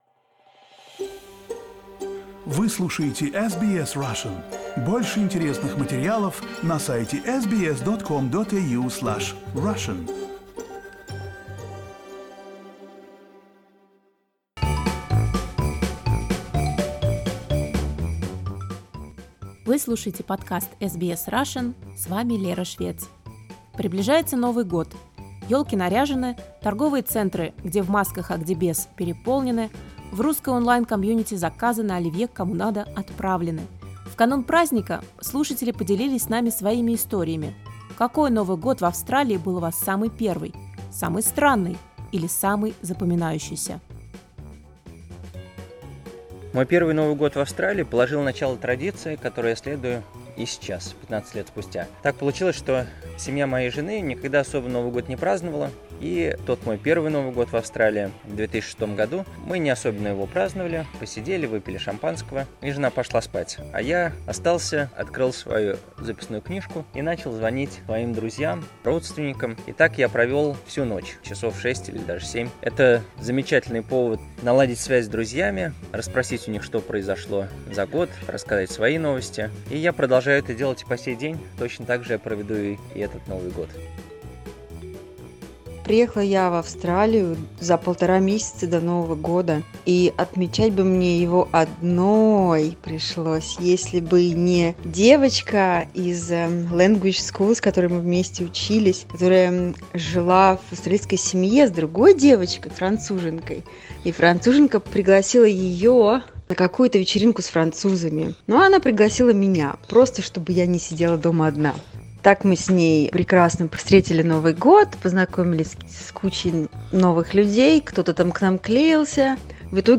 Vox-pop: Your most memorable New Year's Eve
Our listeners share stories of their most memorable New Year celebrations in Australia.